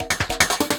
Percussion 16.wav